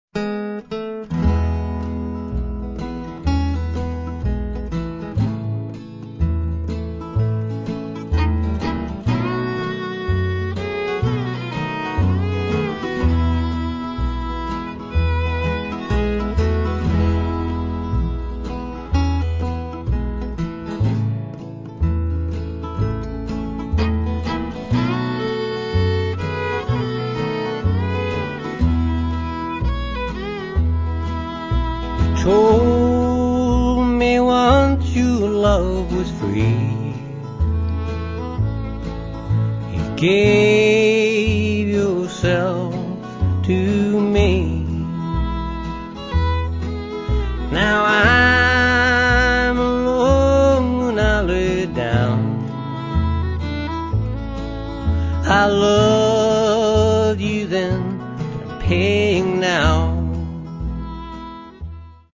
It’s music which sounds warm, sympathetic, and gentle.
We’re talking about blue-grass, country, folk
acoustic guitar